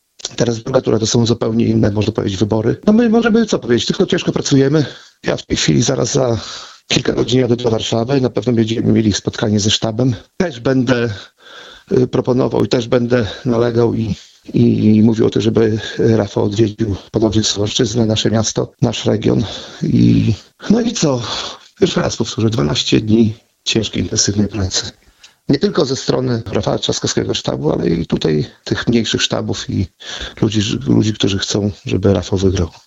– Wszystkie ręce na pokład, walka trwa nadal – tak wyniki pierwszej tury wyborów skomentował Jacek Niedźwiedzki, poseł Platformy Obywatelskiej z Suwałk. Podczas krótkiej rozmowa z Radiem 5 parlamentarzysta stwierdził, że jest pełen nadziei na sukces Rafała Trzaskowskiego w drugiej turze.